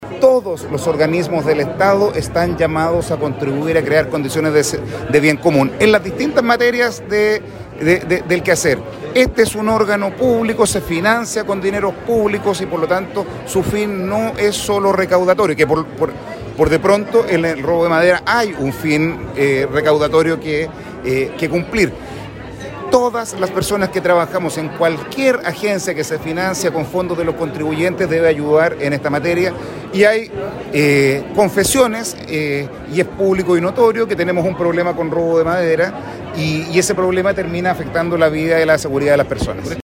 En el marco del evento Impulsa 2022, organizado por la Cámara del Comercio y Producción del Biobío, el Gobernador Regional, Rodrigo Díaz se refirió a las solicitudes planteadas como desafíos desde la zona.